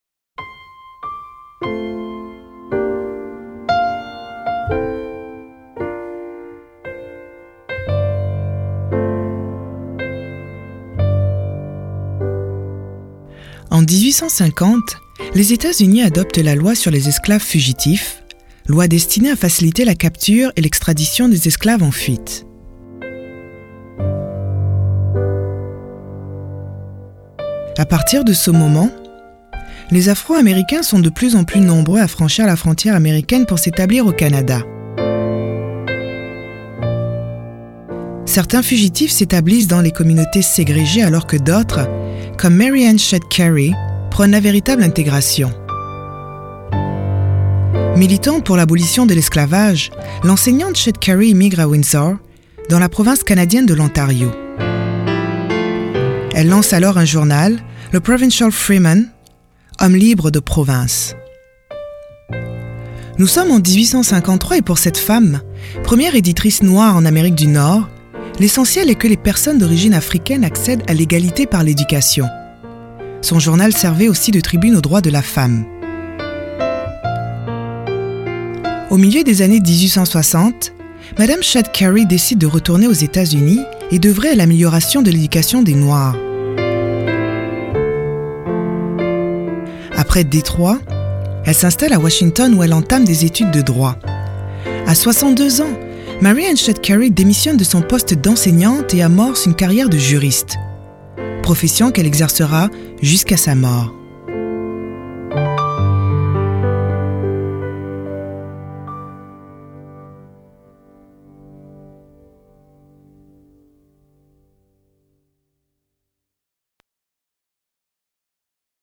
Narratrice: